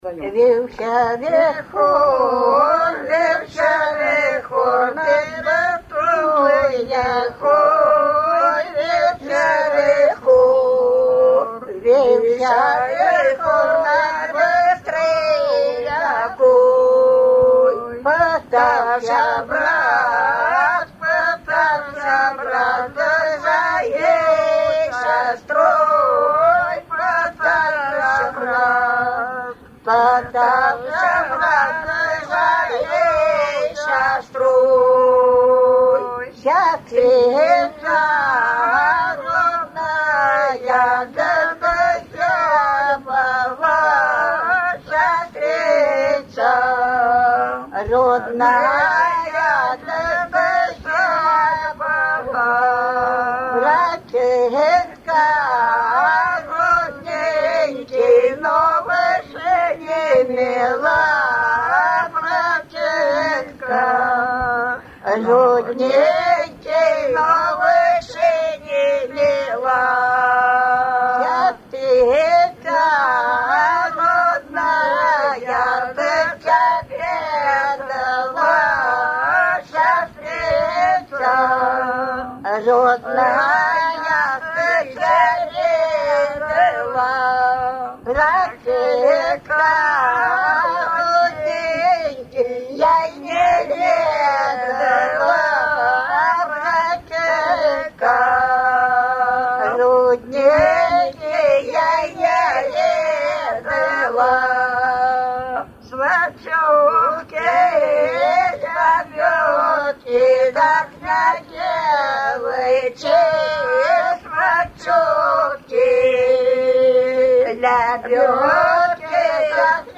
Свадебные обрядовые песни в традиции верховья Ловати